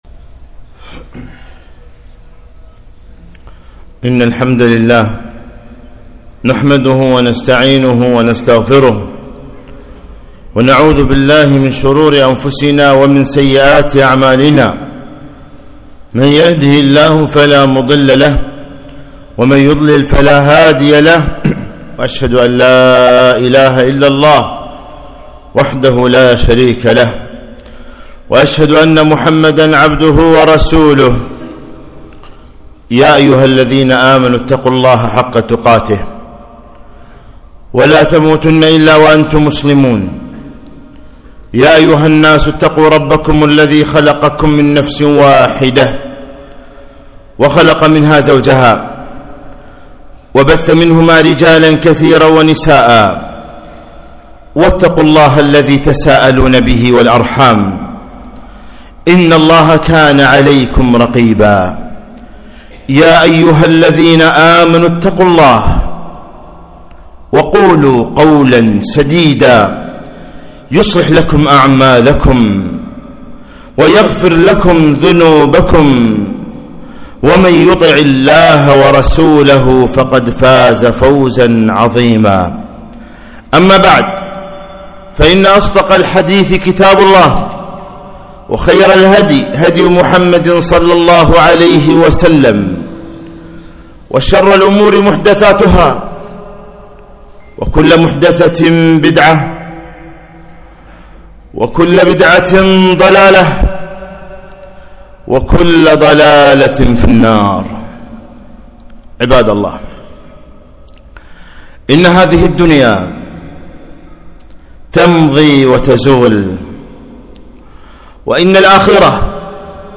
خطبة - كفى بالموت واعظا